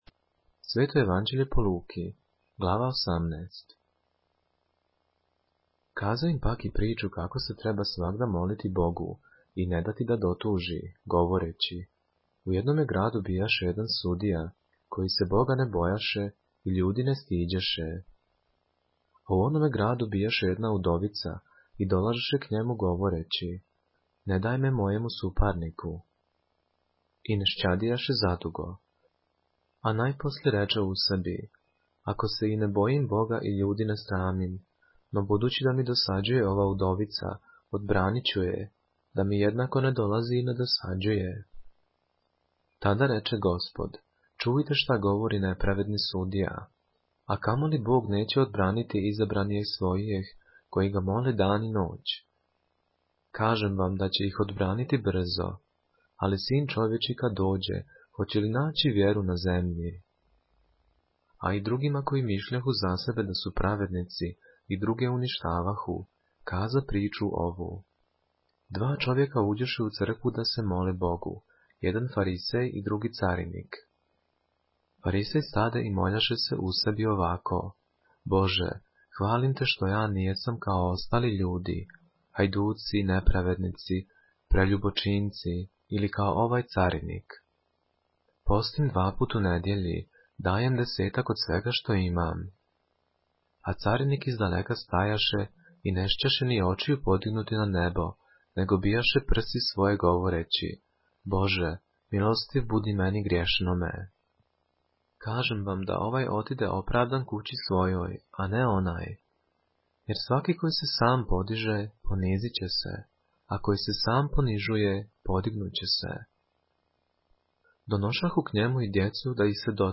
поглавље српске Библије - са аудио нарације - Luke, chapter 18 of the Holy Bible in the Serbian language